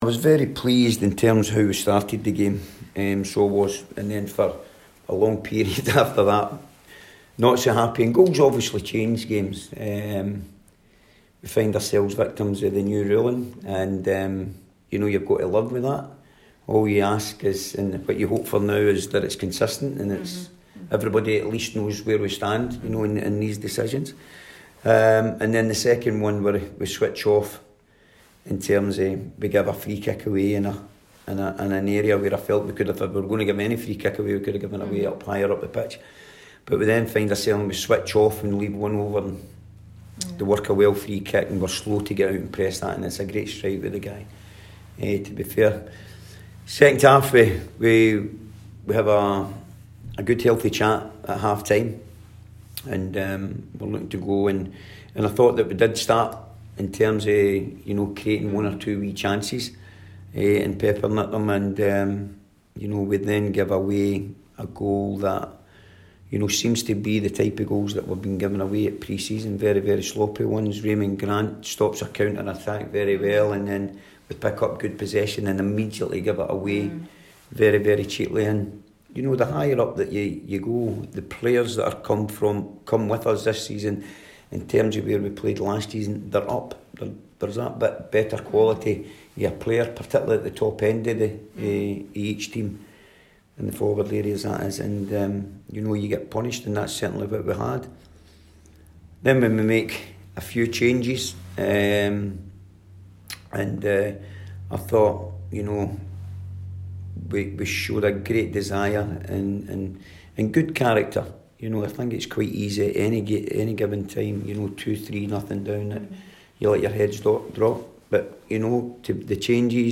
press conference after the Betfred Cup match.